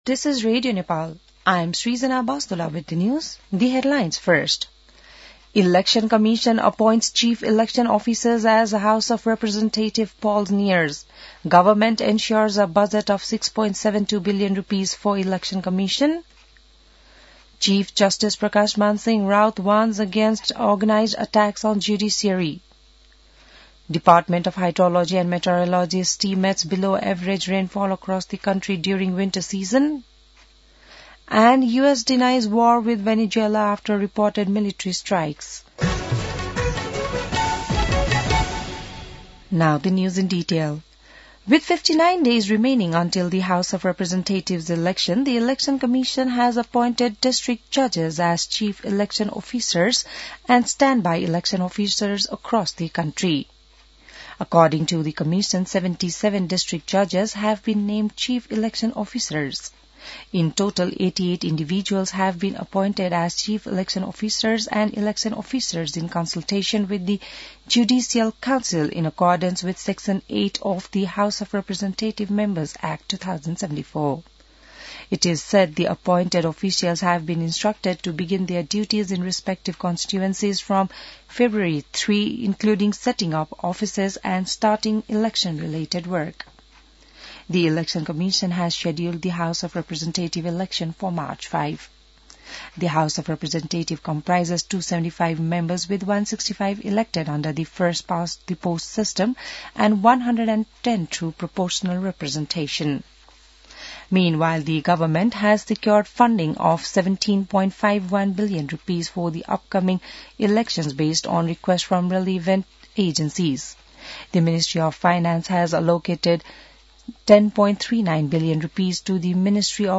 बिहान ८ बजेको अङ्ग्रेजी समाचार : २१ पुष , २०८२